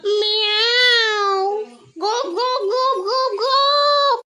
meow ghop ghop ghop Meme Sound Effect
meow ghop ghop ghop.mp3